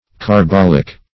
Search Result for " carbolic" : The Collaborative International Dictionary of English v.0.48: Carbolic \Car*bol"ic\ (k[aum]r*b[o^]l"[i^]k), a. [L. carbo coal + oleum oil.]